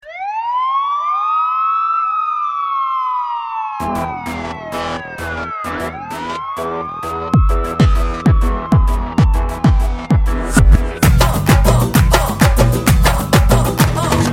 Polis Sireni v14